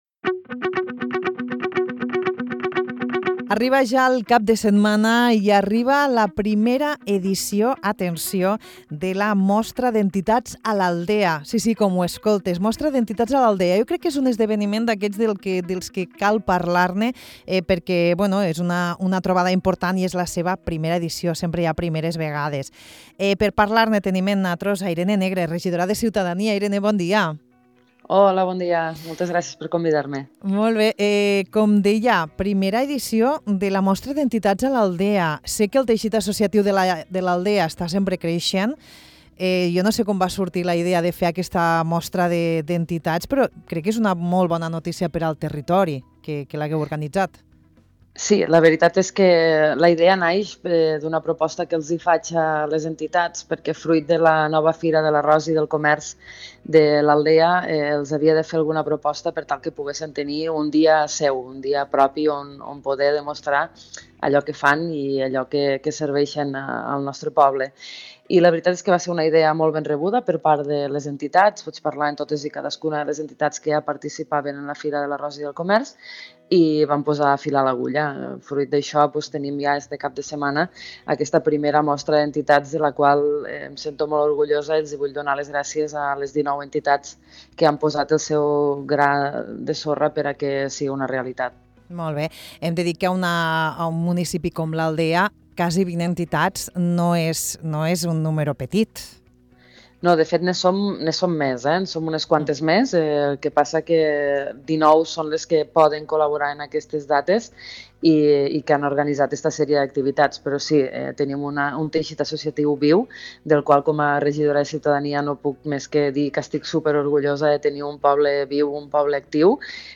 Aquest cap de setmana tindrà lloc la 1a Mostra d’Entitats a l’Aldea. Parlem amb Irene Negre, regidora de Ciutadania, sobre aquesta iniciativa que ha nascut de les ganes de donar al teixit associatiu del municipi la importància que es mereixen.